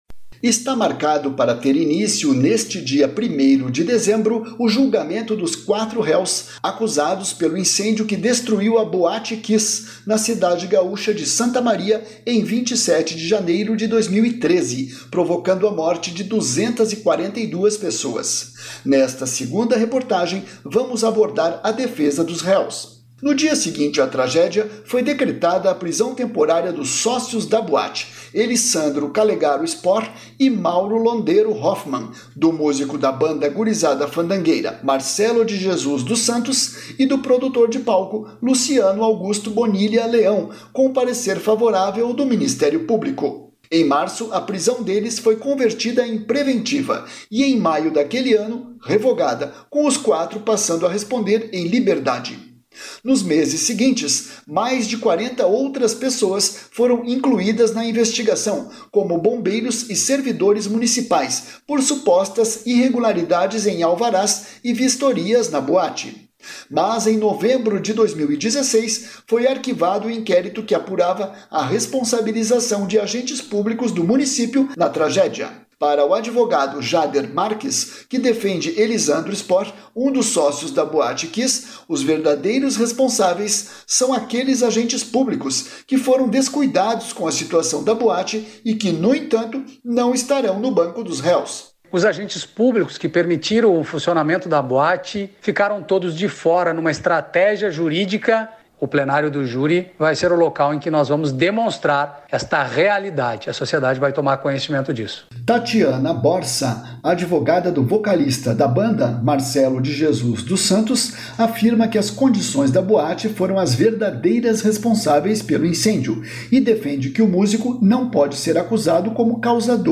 Está marcado para  ter início neste dia 1º de dezembro, o julgamento dos quatro réus, acusados pela incêndio que destruiu a boate Kiss, na cidade gaúcha de Santa Maria, em 27 de janeiro de 2013, provocando a morte de 242 pessoas. Nesta segunda reportagem, vamos abordar a defesa dos réus.